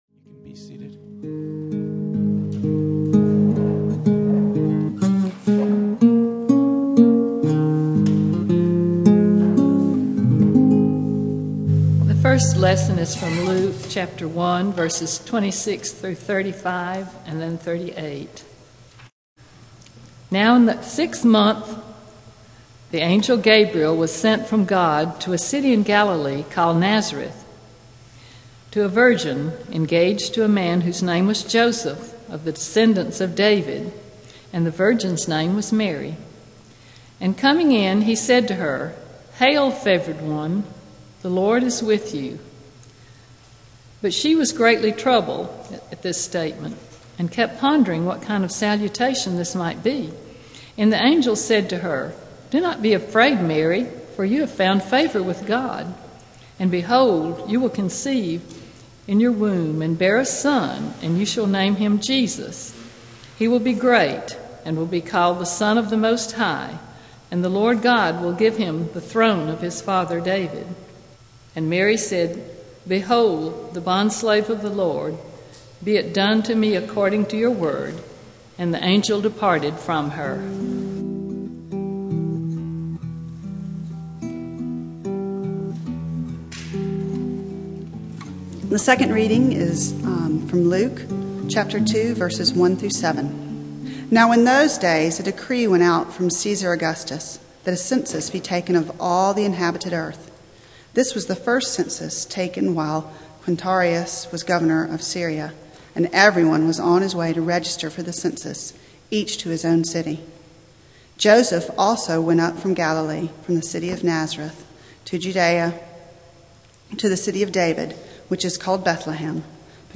Passage: Micah 4:1-5:10 Service Type: Sunday Morning